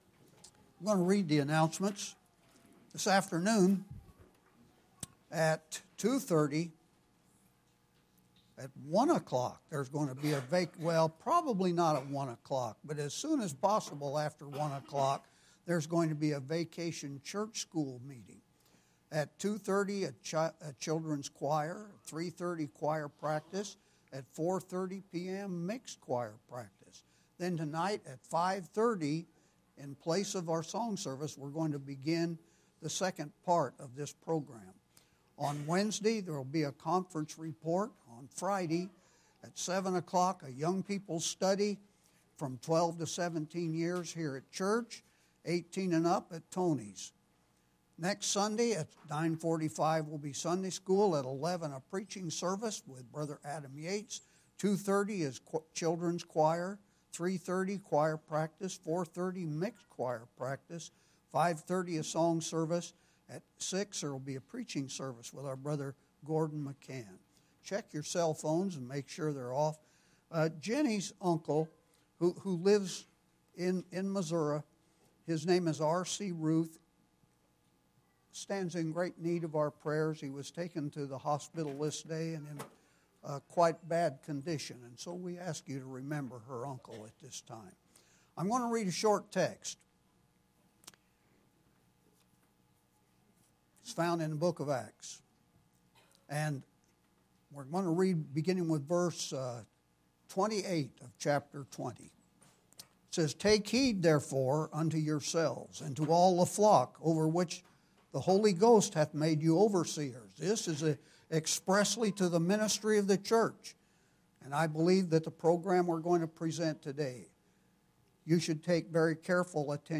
This is an in-depth presentation to make us aware of Satan’s plan to reinvent church worship to a new post-modern way.